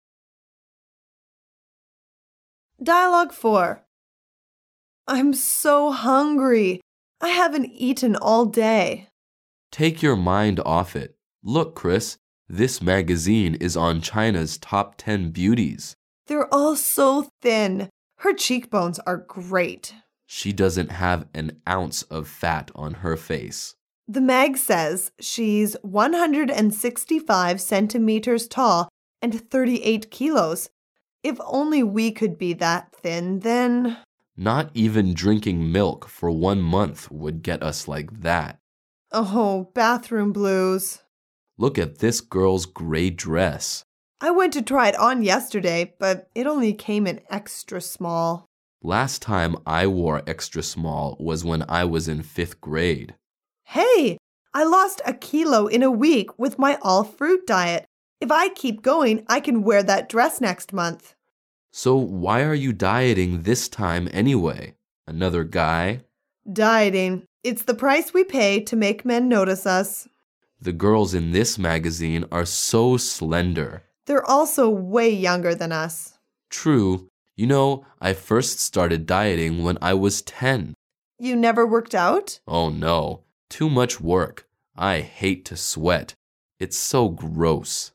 Dialouge 4